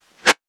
metahunt/weapon_bullet_flyby_07.wav at master
weapon_bullet_flyby_07.wav